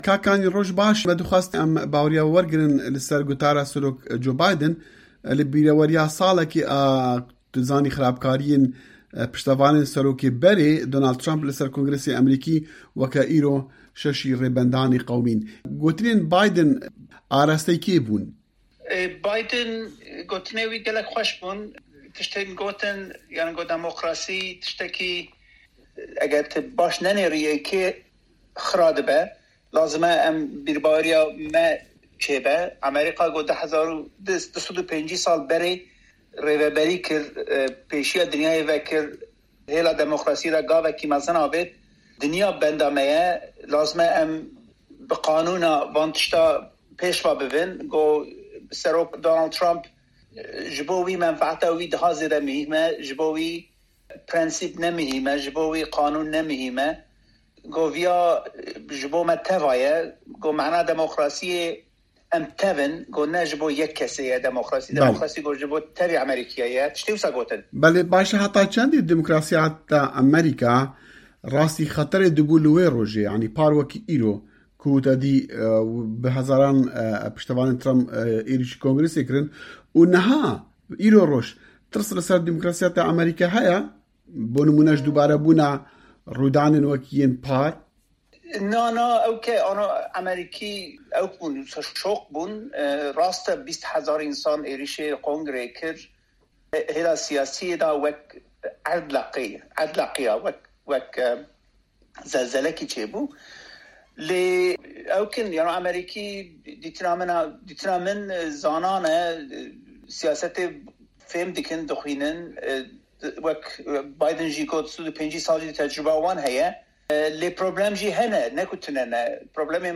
Hevpeyvîn